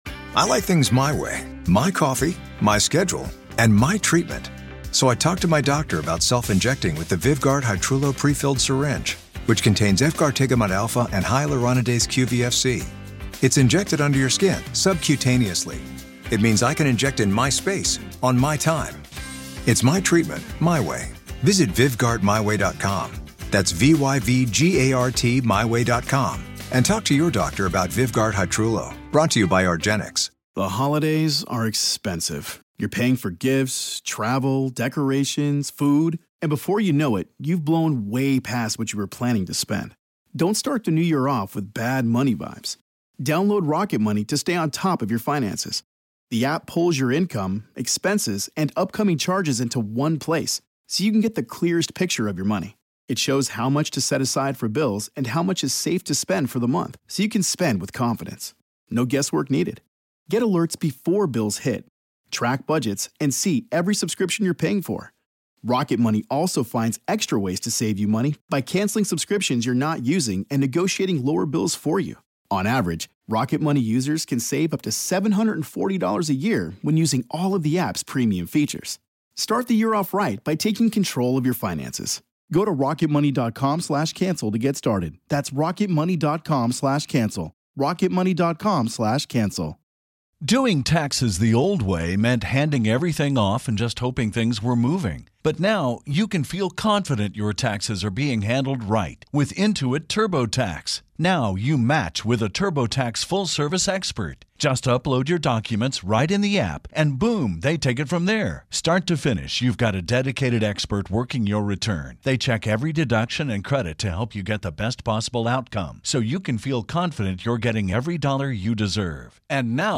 This is Part One of our conversation.